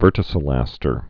(vûrtĭ-sə-lăstər)